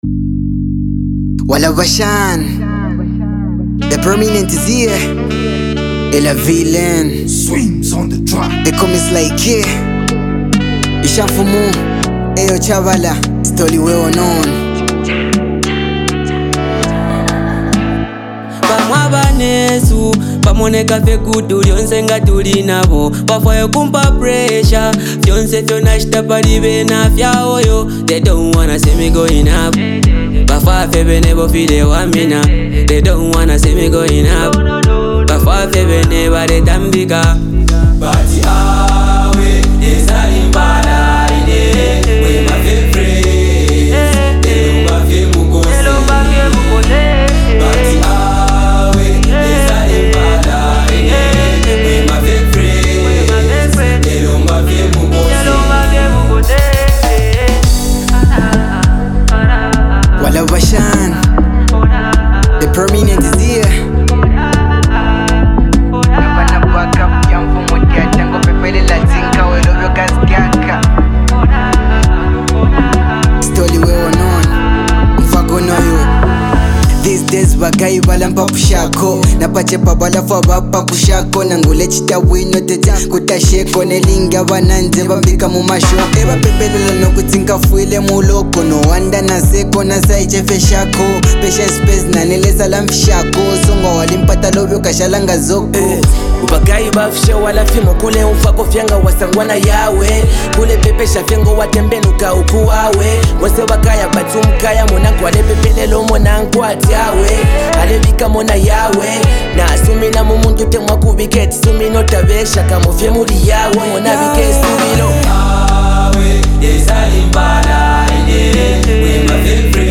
captures the vibrant energy of Zambia‘s modern sound
Genre: Afro Pop, Zambia Songs